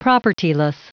Prononciation du mot propertyless en anglais (fichier audio)
Prononciation du mot : propertyless